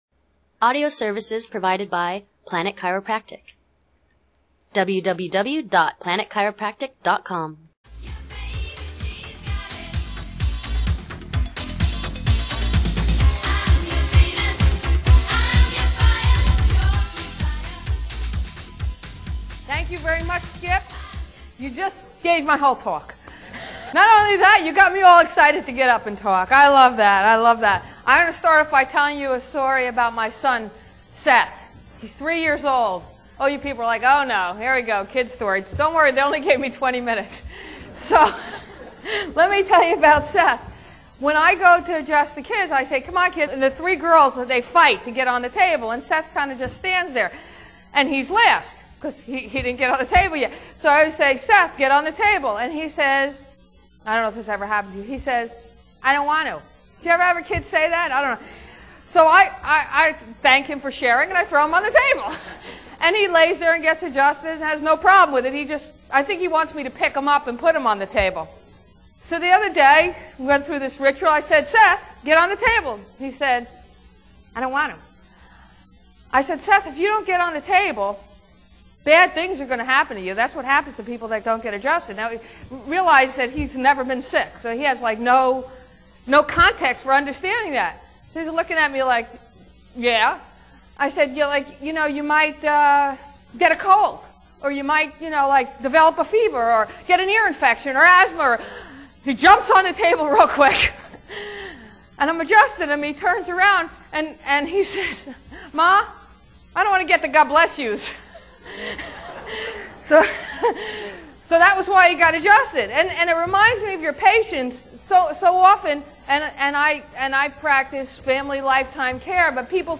This month Planet Chiropractic brings you yet another inspirational chiropractic talk from a well known and very successful chiropractor.